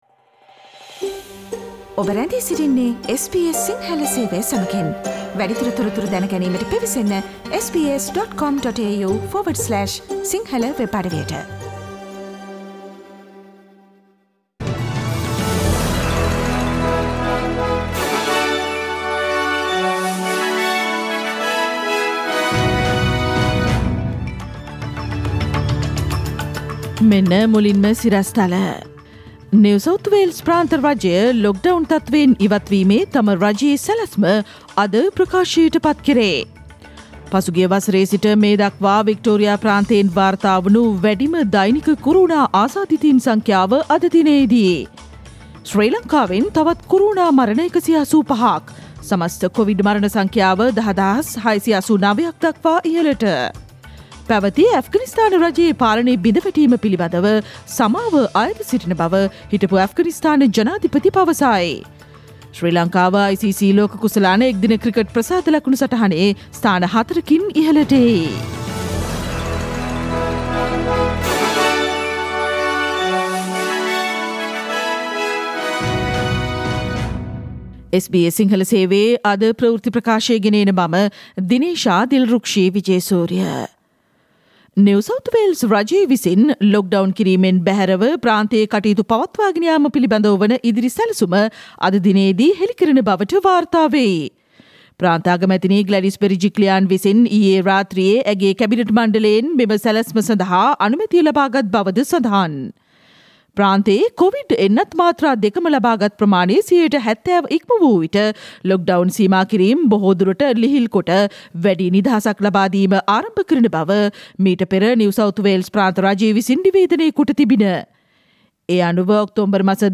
සැප් 9දා SBS සිංහල ප්‍රවෘත්ති: මේ දක්වා වික්ටෝරියා ප්‍රාන්තයෙන් වාර්තා වුනු වැඩිම කොරෝනා ආසාදිතයින් සංඛ්‍යාව අද දිනයේදී